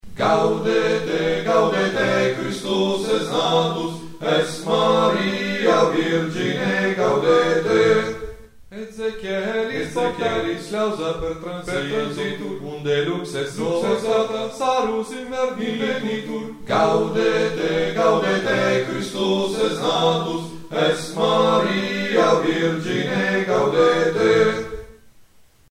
CORO